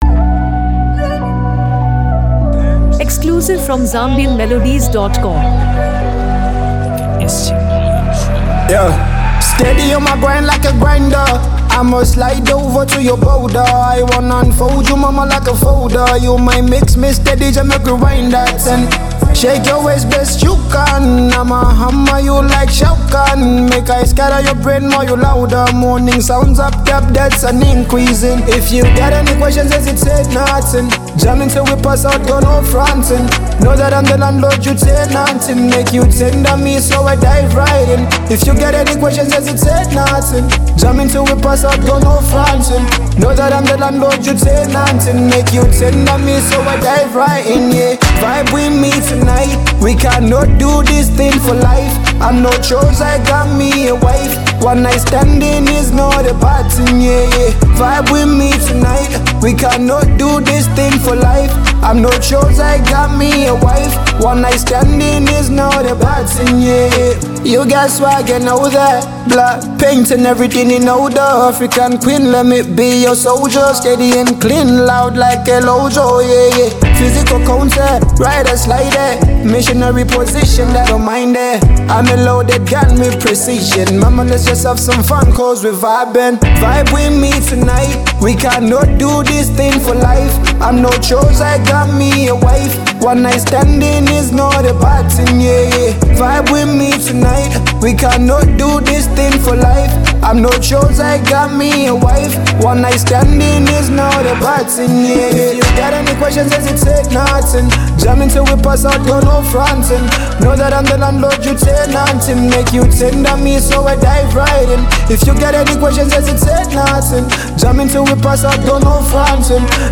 A Smooth Blend of Emotion and R&B Flair
With silky vocals layered over a mellow beat